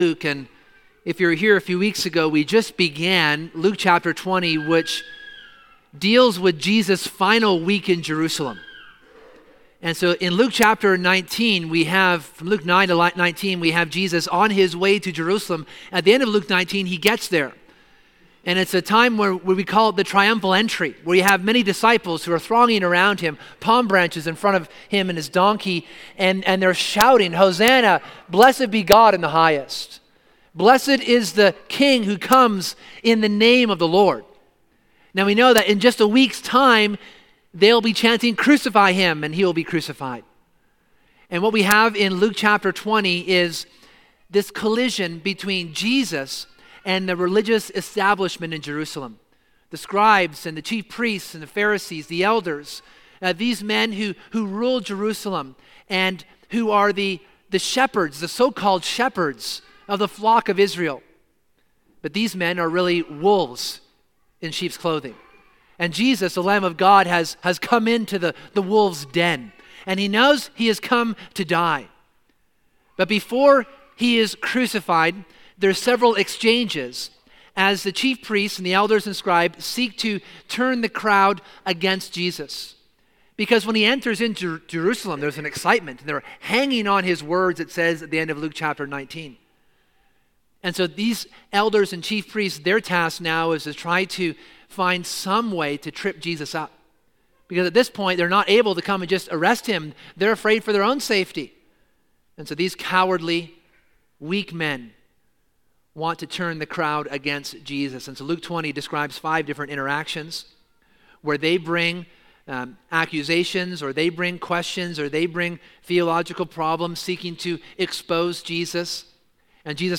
In this sermon we considerÂ Jesus’ parableÂ of the tenants. This allegory is a history of Israel and their rejection of Christ, God’s beloved Son.